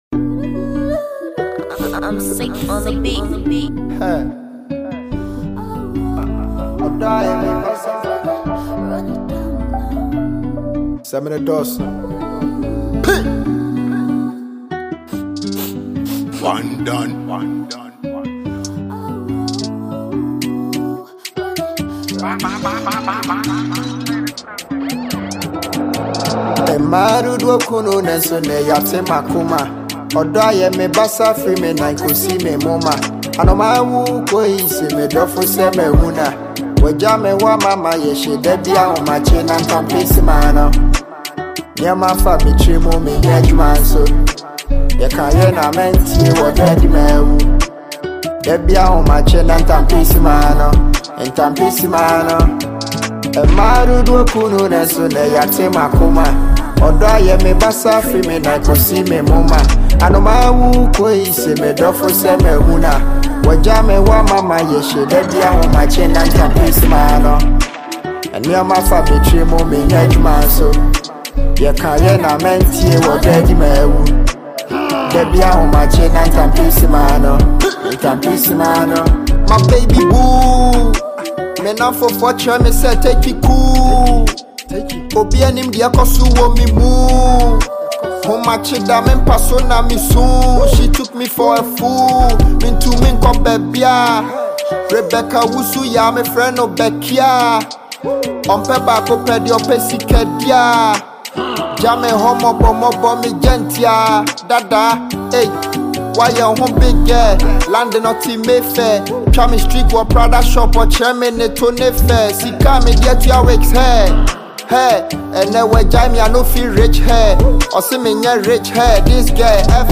a dope tune
This is a banger all day.